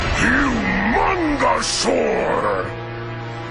[Voice Example]